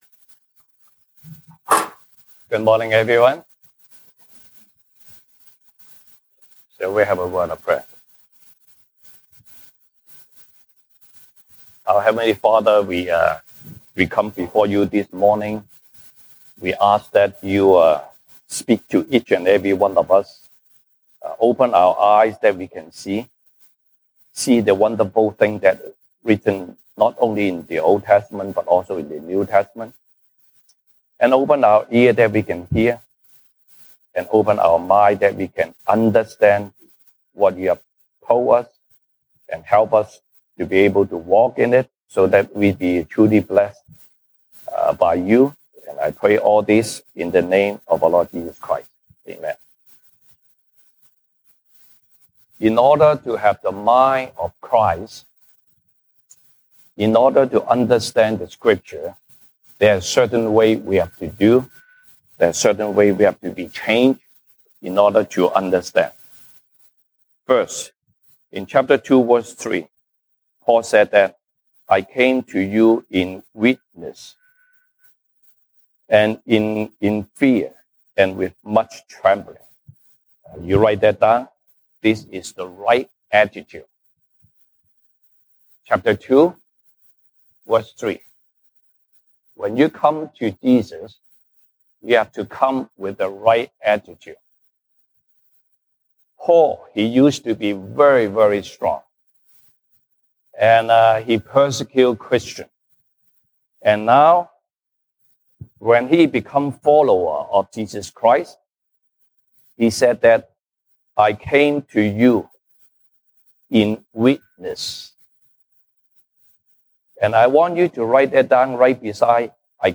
西堂證道 (英語) Sunday Service English: Why & How Paul serves God with fear and trembling?